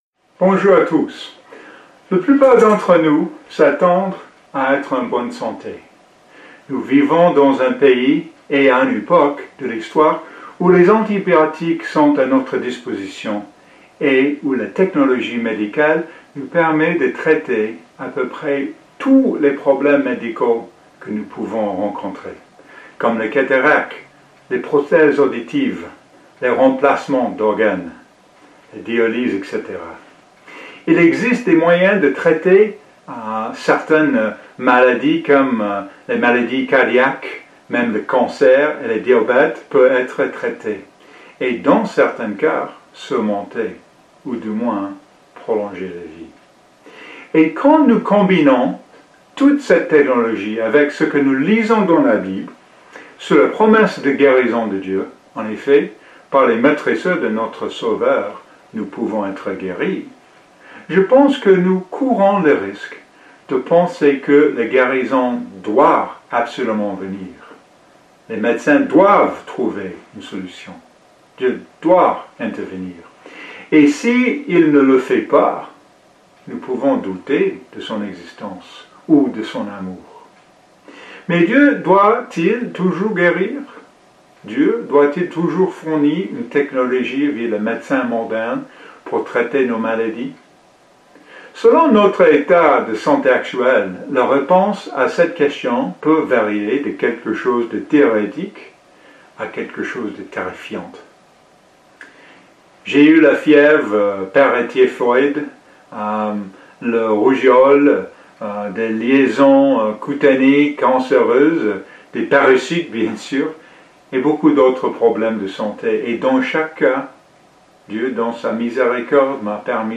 Quand nous combinons toute cette technologie avec ce que nous lisons dans la bible sur la promesse de guérison de Dieu, est-ce qu’on peut penser que Dieu doive nous guérir ? Dans ce sermon